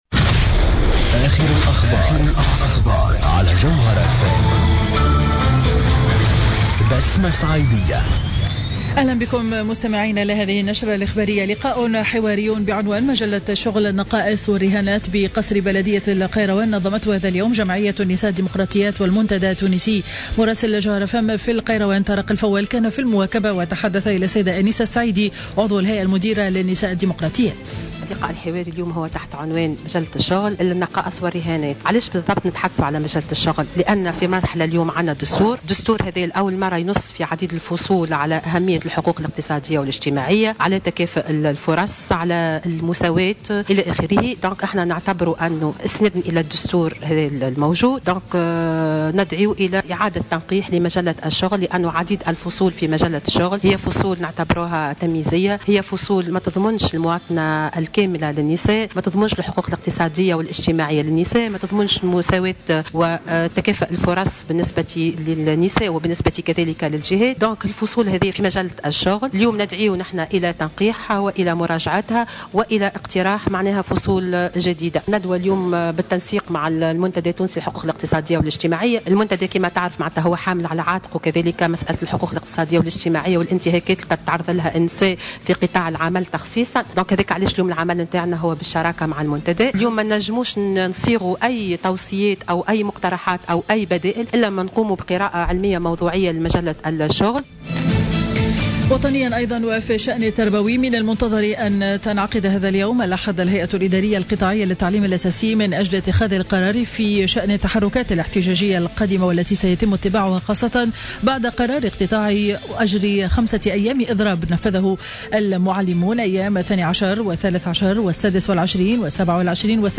نشرة أخبار منتصف النهار ليوم الأحد 14 جوان 2015